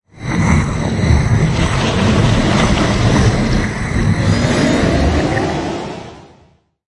科幻的声音效果 (22)
从ZOOM H6录音机和麦克风Oktava MK01201领域录制的效果，然后处理。
Tag: 未来 托管架 无人驾驶飞机 金属制品 金属 过渡 变形 可怕 破坏 背景 游戏 黑暗 电影 上升 恐怖 开口 命中 噪声 转化 科幻 变压器 冲击 移动时 毛刺 woosh 抽象的 气氛